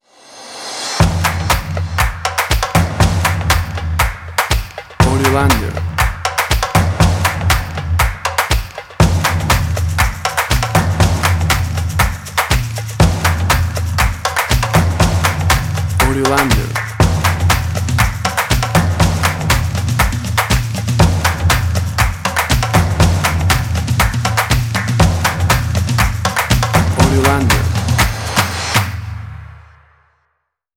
Tempo (BPM): 120